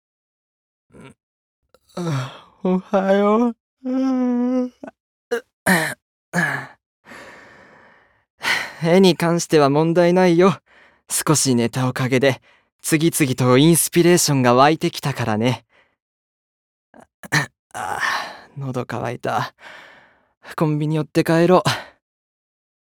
ボイスサンプル
マイペースな先輩